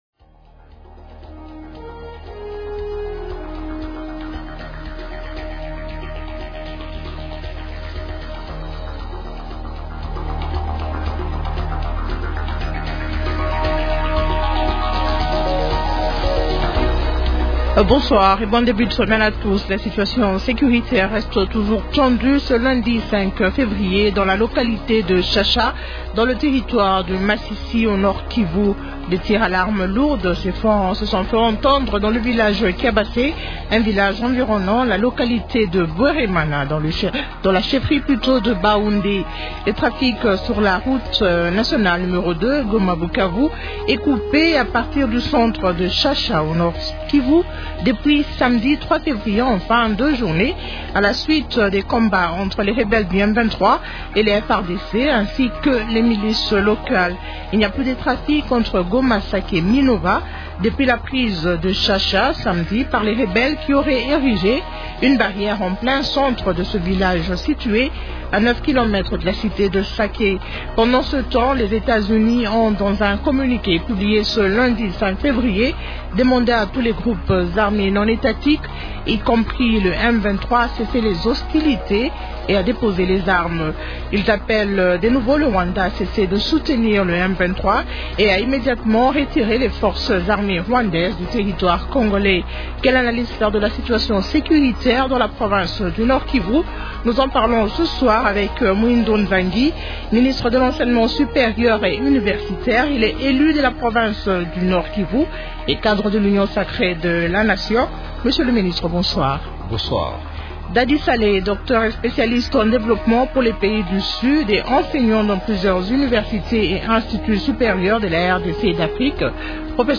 Question : -Quelle analyse faire de la situation sécuritaire dans la province du Nord-Kivu ? Invités : -Muhindo Nzangi, ministre de l’Enseignement supérieur et universitaire.